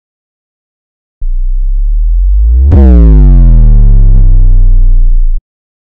جلوه های صوتی
دانلود آهنگ ضربه باس 2 از افکت صوتی اشیاء
دانلود صدای ضربه باس 2 از ساعد نیوز با لینک مستقیم و کیفیت بالا